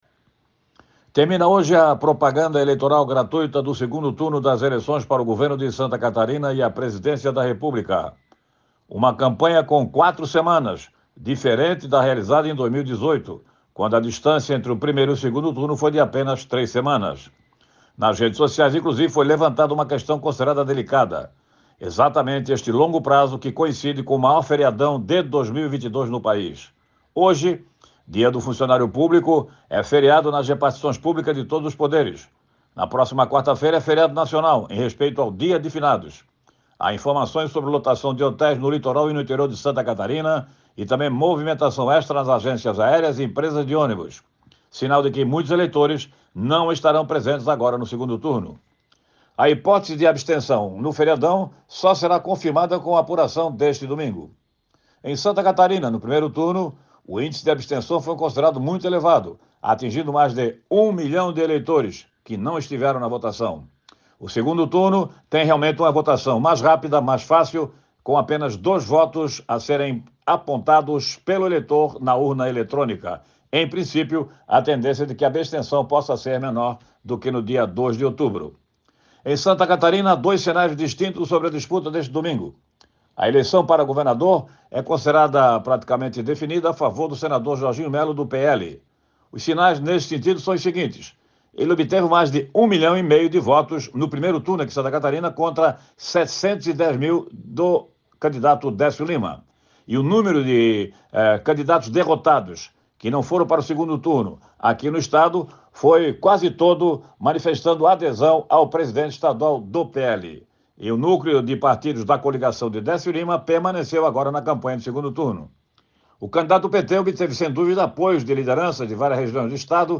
Jornalista trata do término das campanhas eleitorais gratuitas pelo rádio e televisão e o ativismo praticado pelo TSE durante a campanha do segundo turno